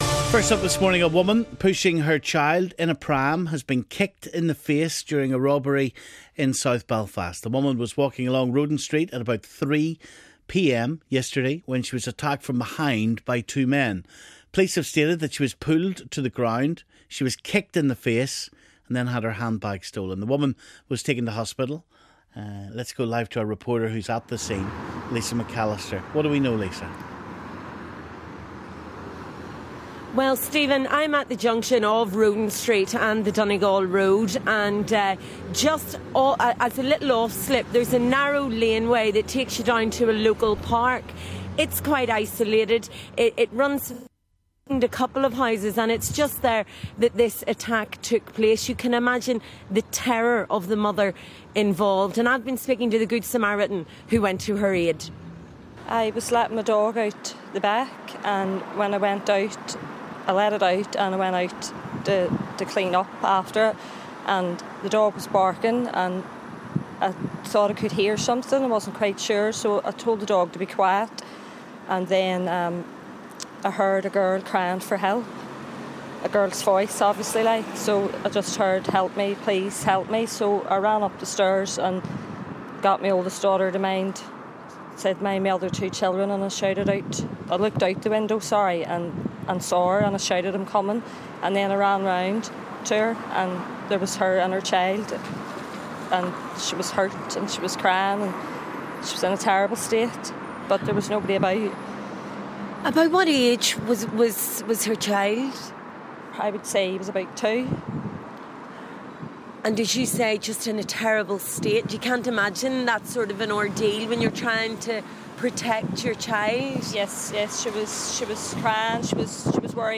speaks to an eyewitness